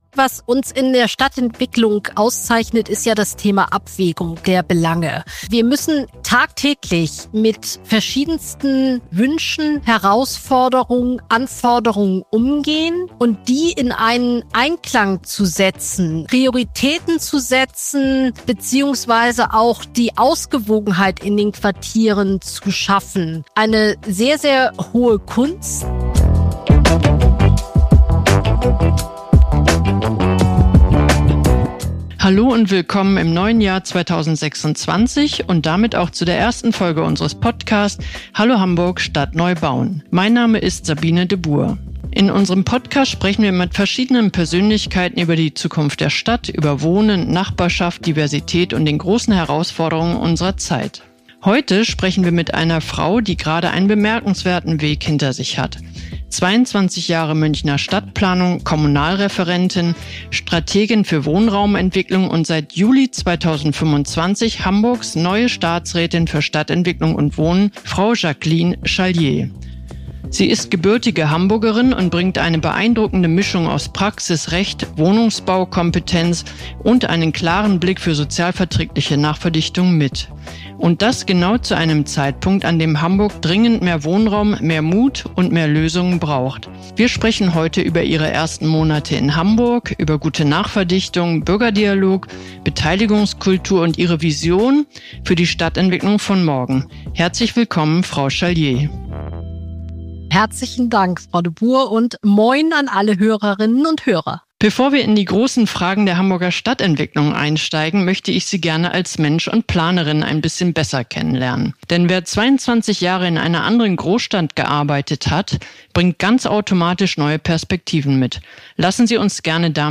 Leidenschaft für Stadtentwicklung: Staatsrätin Jacqueline Charlier im Gespräch mit der IBA Hamburg ~ Hallo Hamburg! Stadt neu bauen - der Podcast